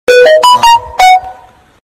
Android Beep